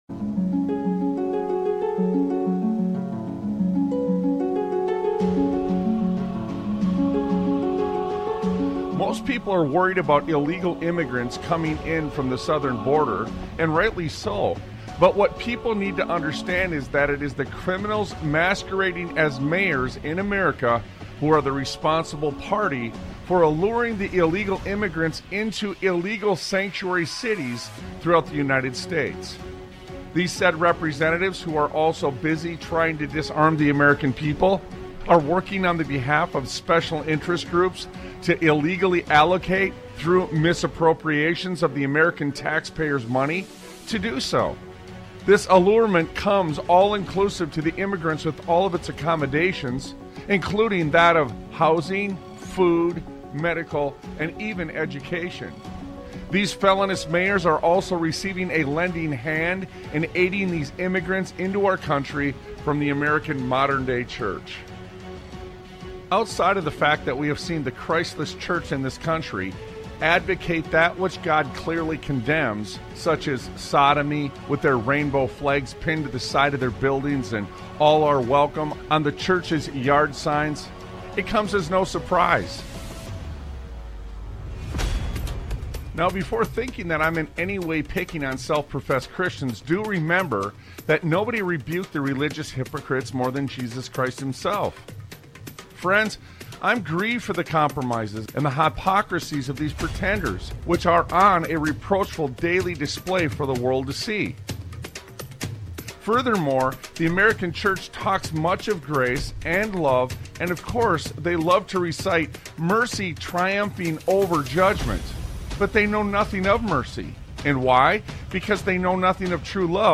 Sons of Liberty Radio